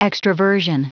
Prononciation du mot extraversion en anglais (fichier audio)
Prononciation du mot : extraversion